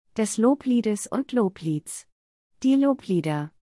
/ˈloːpliːt/ · /ˈloːpliːtəs/ · /ˈloːpliːdɐ/